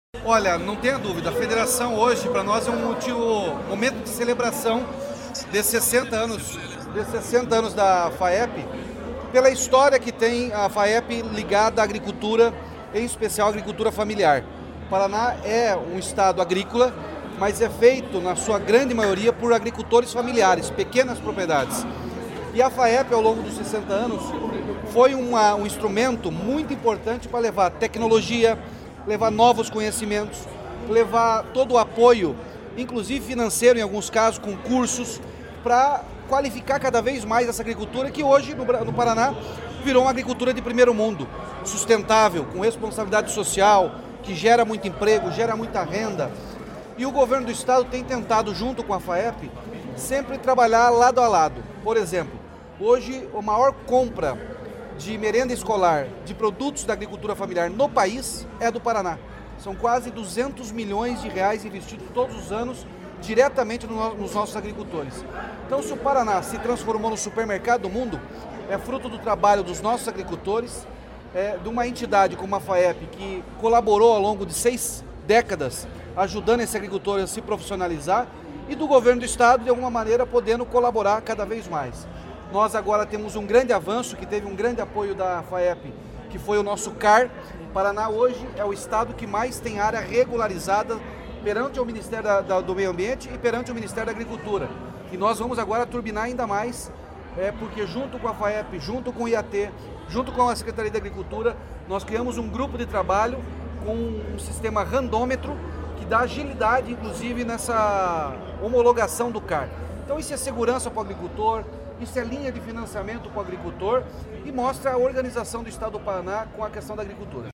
Sonora do governador Ratinho Junior sobre o lançamento do programa CertiCAR